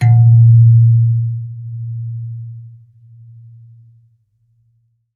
kalimba_bass-A#1-ff.wav